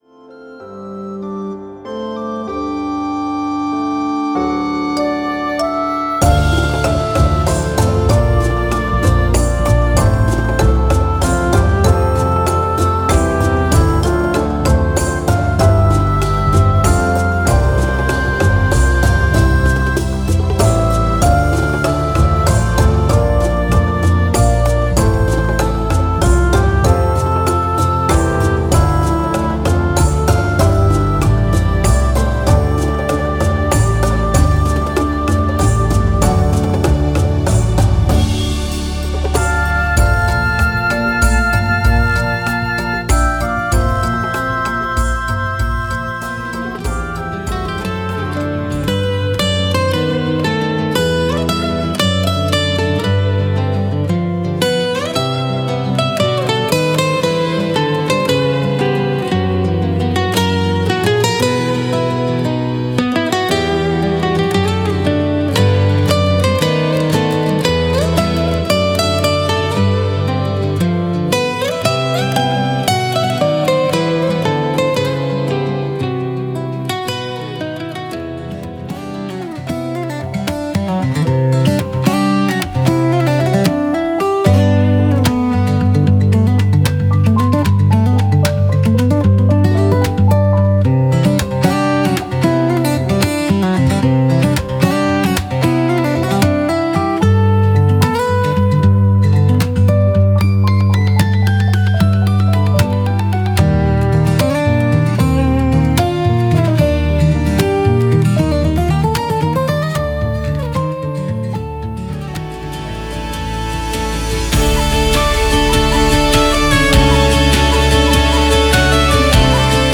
アコースティックとケルティック要素を
クロスフェードデモ